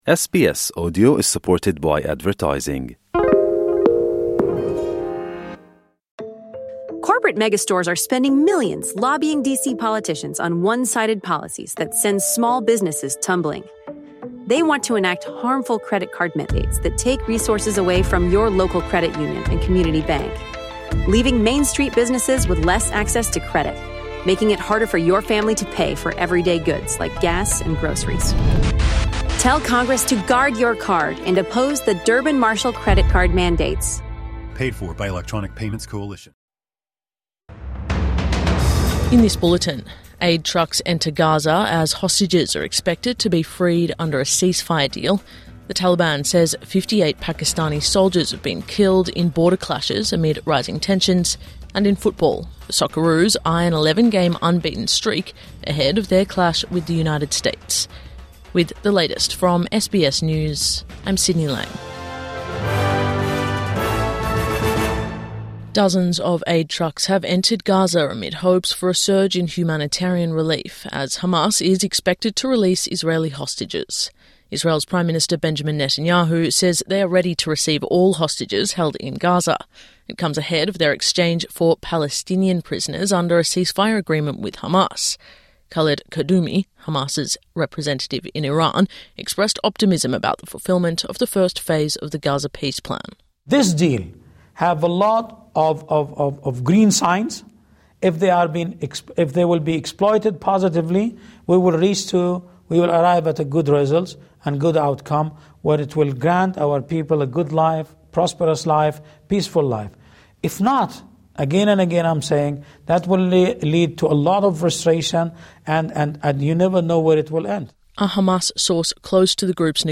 Gaza: Aid begins to roll in | Morning News Bulletin 13 October 2025